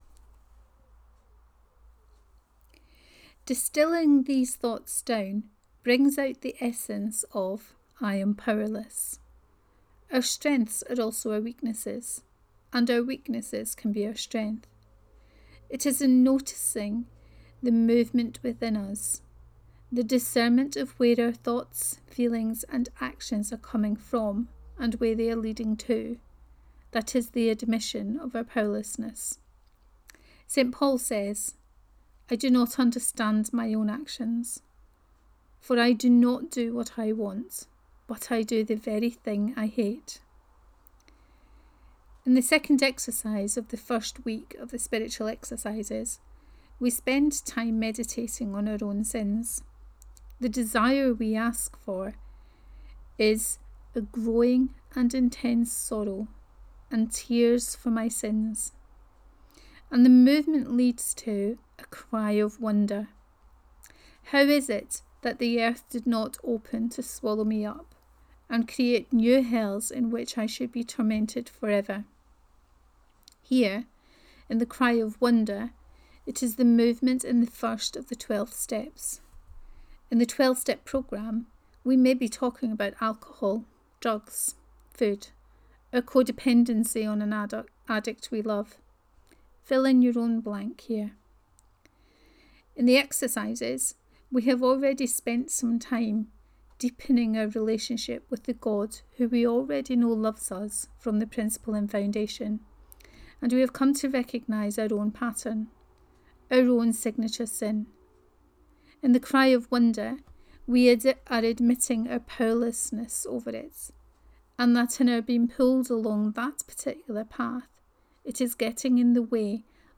Step 1 and The Spiritual Exercises 3: Reading of this post